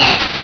pokeemerald / sound / direct_sound_samples / cries / torkoal.aif